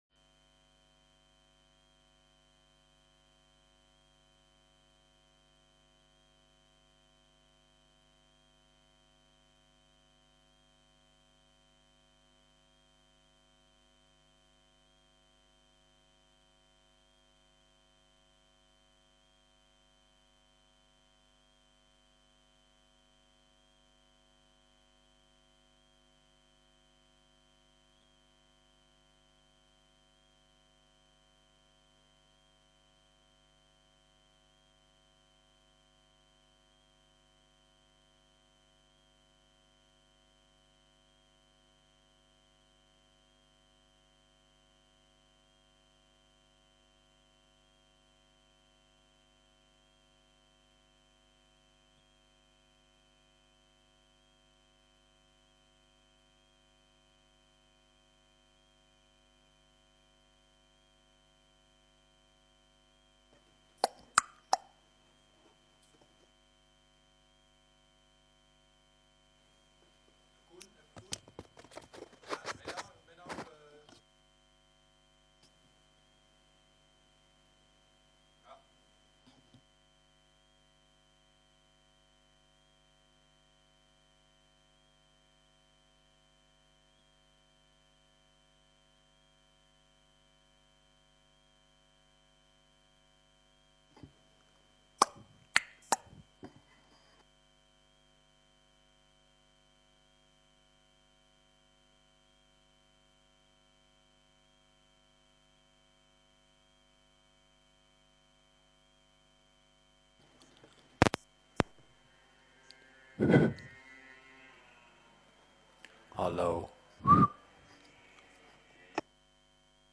Download de volledige audio van deze vergadering